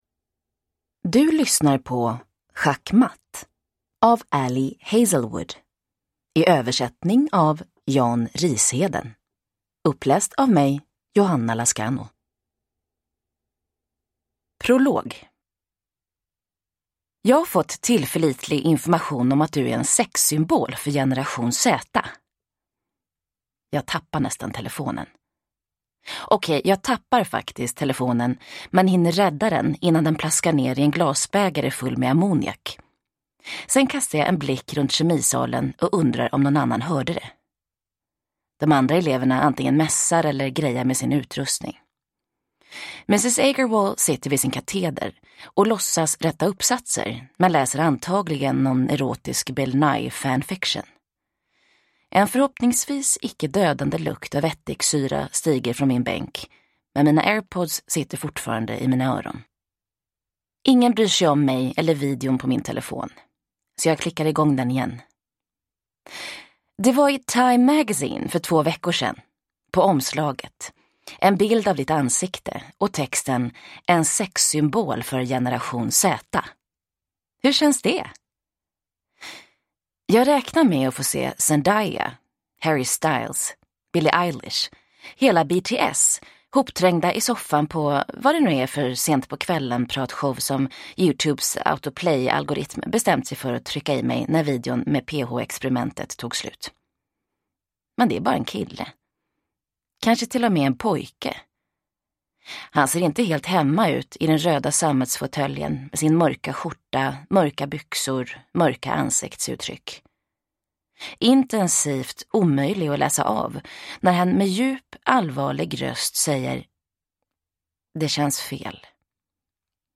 Schackmatt – Ljudbok